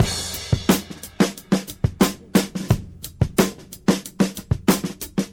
break